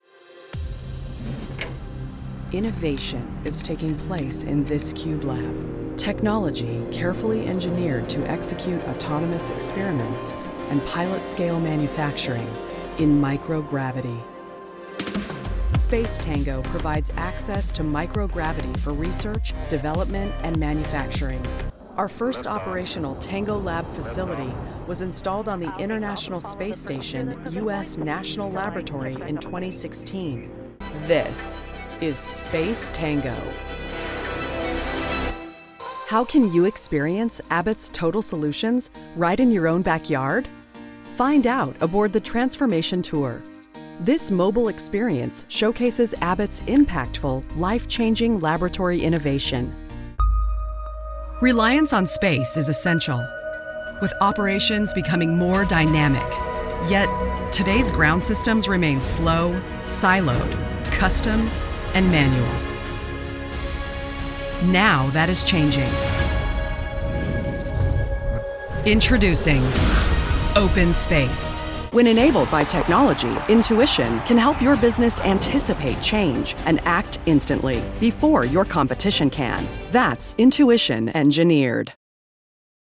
Wise, authentic, commanding, warm, young, friendly, sincere, and inclusive.
Neutral American